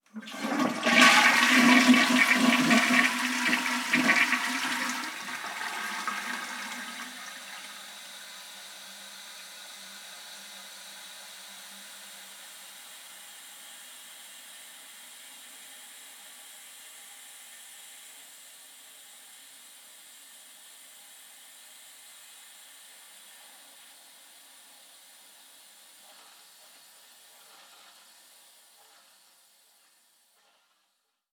Cisterna de wáter 1
cisterna
Sonidos: Agua
Sonidos: Hogar